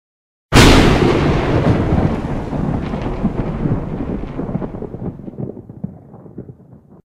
thunder0.ogg